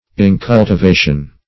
Incultivation \In*cul`ti*va"tion\, n. Lack of cultivation.